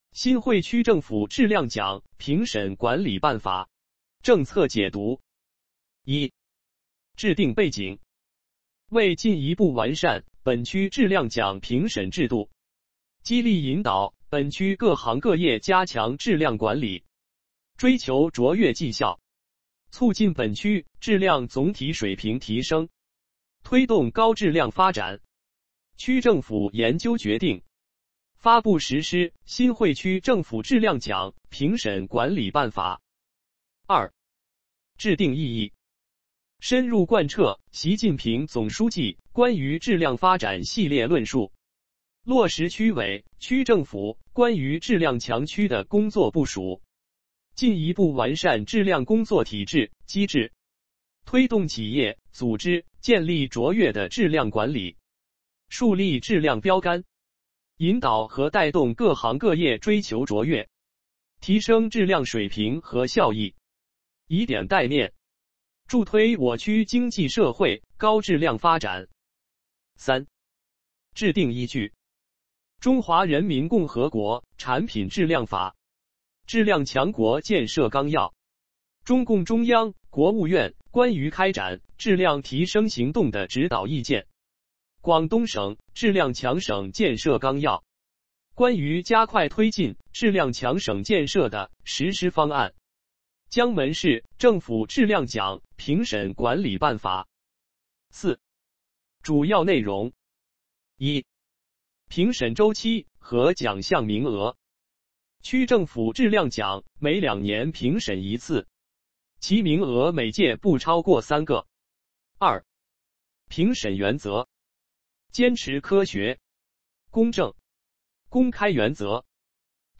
语音解读